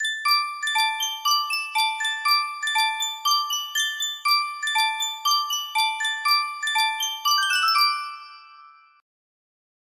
Witty Banter music box melody
Full range 60
Experimental piece with an arpeggio charm.